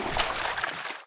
pl_slosh4.wav